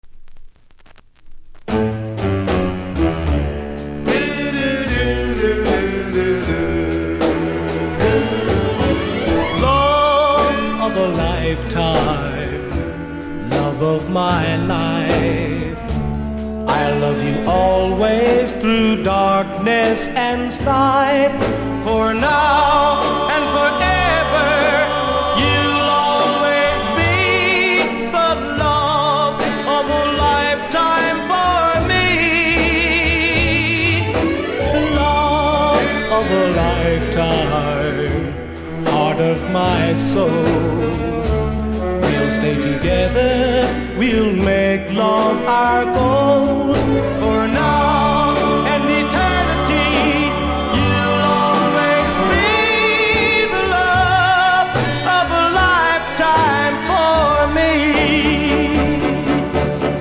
60'S MALE GROUP